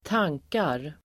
Uttal: [²t'ang:kar]